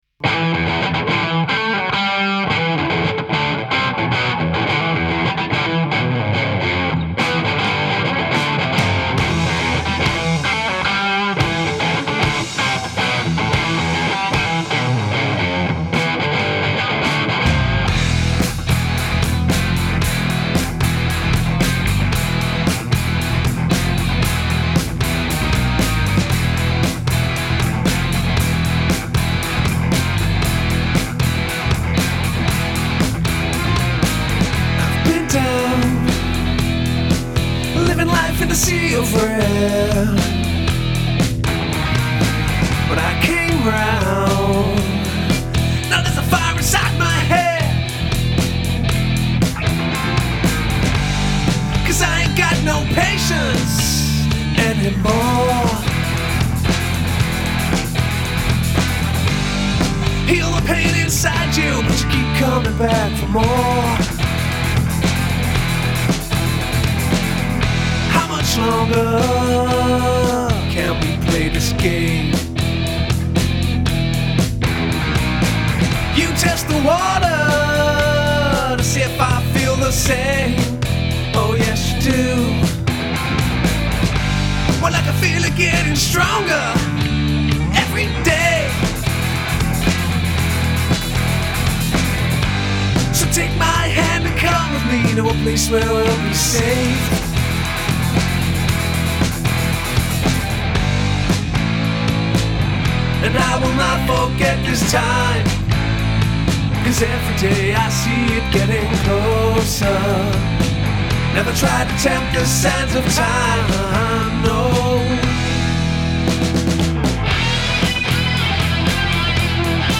LEAD GUITAR & VOCALS
DRUMS
RYTHM GUITAR & VOCALS
BASS GUITAR